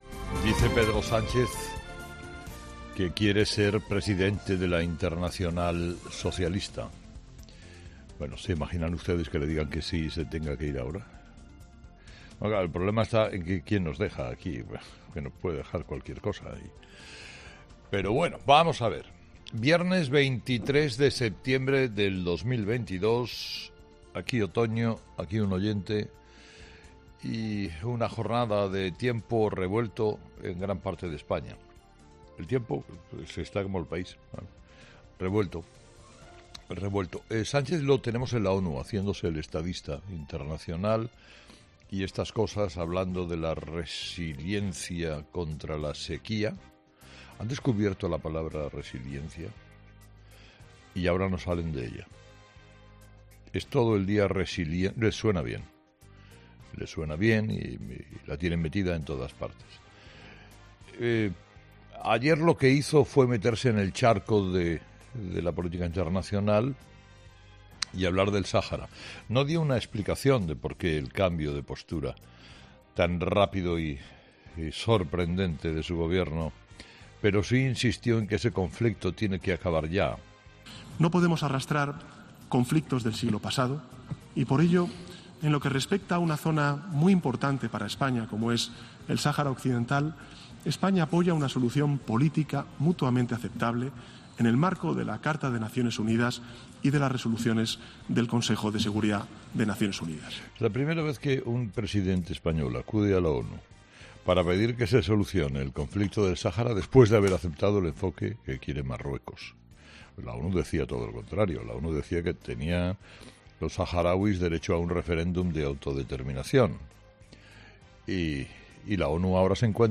Carlos Herrera, director y presentador de 'Herrera en COPE', ha comenzado el programa de este viernes analizando las principales claves de la jornada, que pasan, entre otros asuntos, por la intervención del presidente del Gobierno, Pedro Sánchez, en el seno de la ONU y por las últimas reacciones a la decisión de la Junta de Andalucía de suprimir el impuesto de Patrimonio en esta comunidad autónoma.